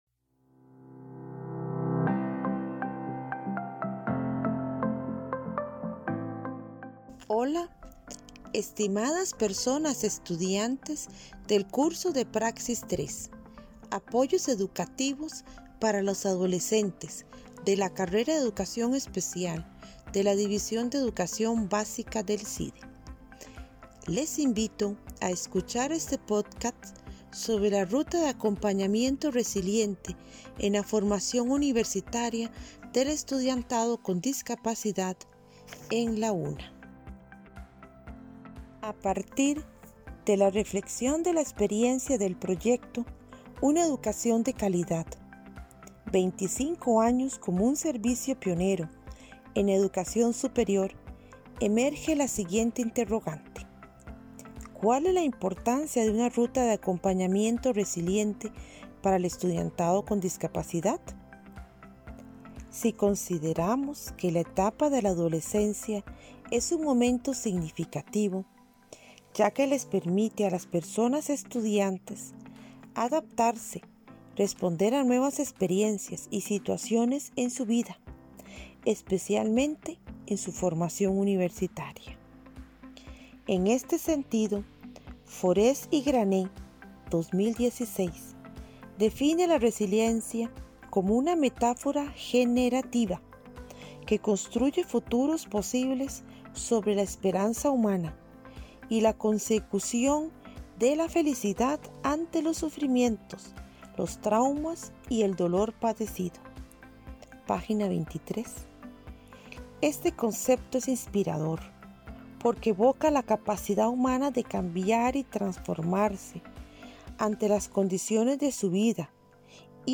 Se presenta una narración sobre la experiencia de vida una joven oriunda de Guanacaste dando el primeros pasos en su formación universitaria. Se desataca los factores de enclave resiliente y las capacidades en la Ruta Inclusiva de Acompañamiento Resiliente en la UNA.